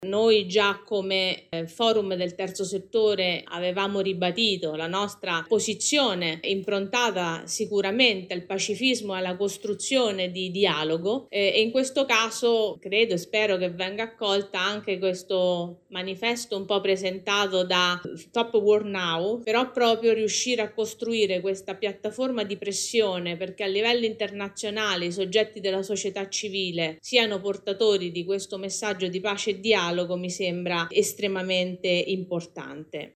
intervenendo ieri all’Assemblea dei soci